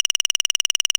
scroll_004.ogg